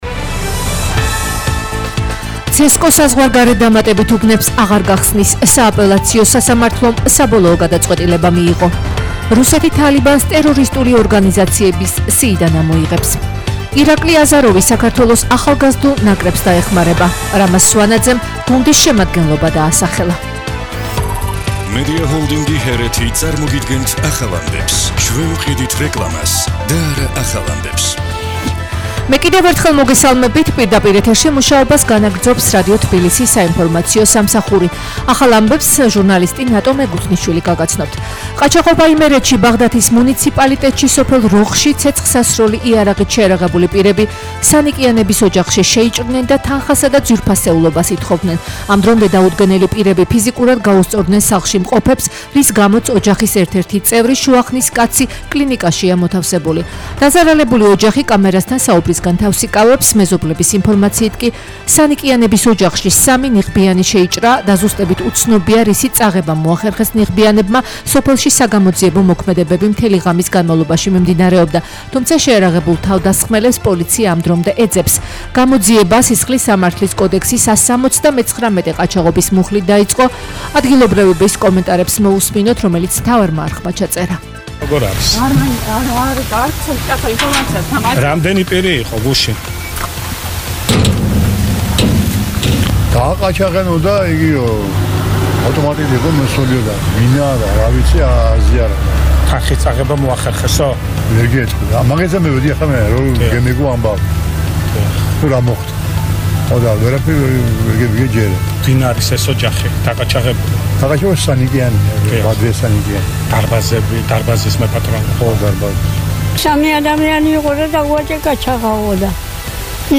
ახალი ამბები 16:00 საათზე